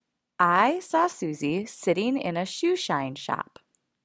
初心者のための発音練習なので、ゆっくりめに発音してもらいました。